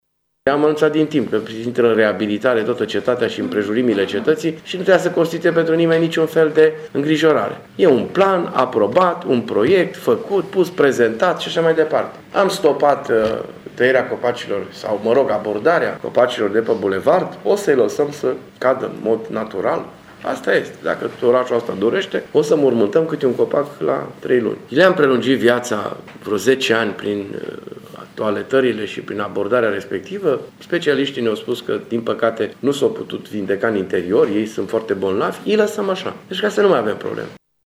Primarul Dorin Florea a explicat, azi, într-o conferință de presă, că în zonă se dorea crearea unei căi de acces pentru autocare, dar, din cauza reacției opiniei publice, s-a luat decizia să nu se mai taie niciun arbore: